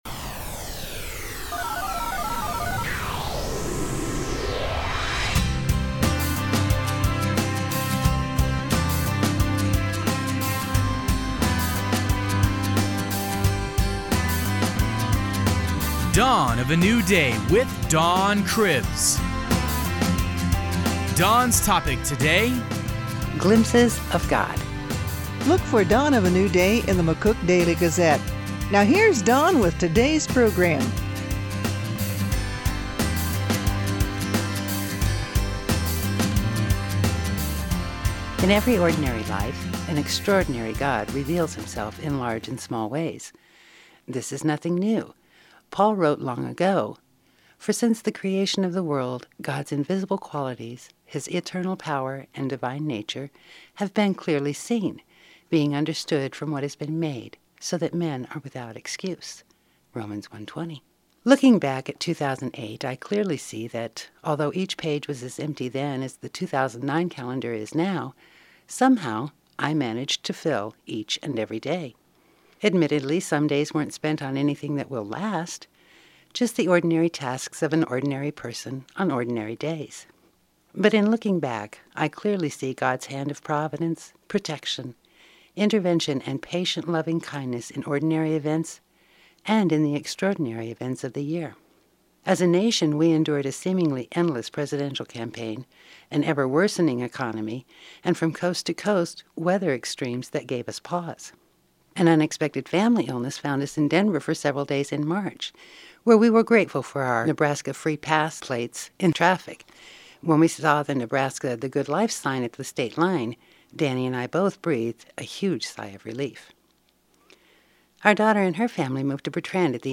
Audio from KNGN 1360 AM: